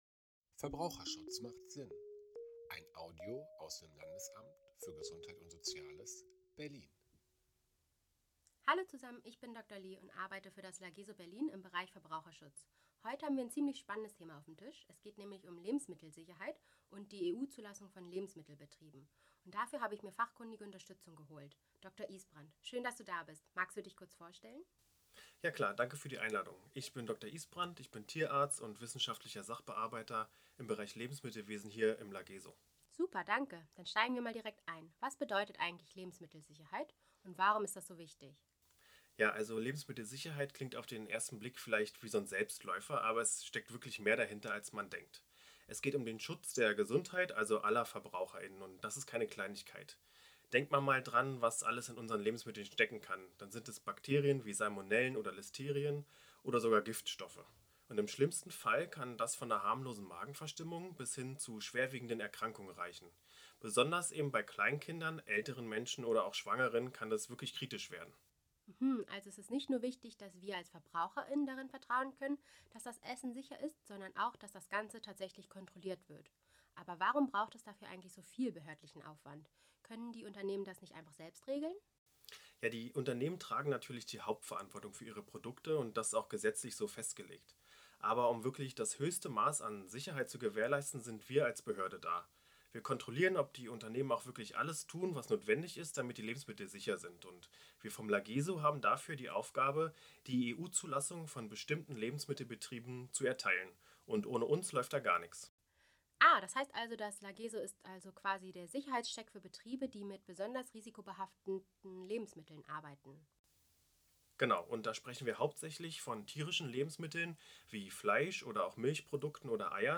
Unser Expertentalk
Was steckt hinter der EU-Zulassung von Lebensmittelbetrieben? In unserem Audio-Expertentalk erläutern zwei Fachleute aus dem Bereich Lebensmittelwesen im Lageso verständlich und praxisnah, wie Betriebe ihre Zulassung erhalten und was das für sichere Lebensmittel bedeutet.